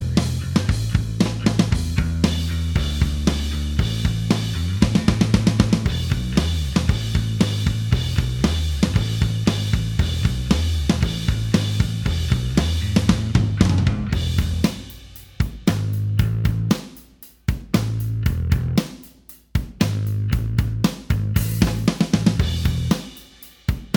Minus All Guitars Indie / Alternative 3:30 Buy £1.50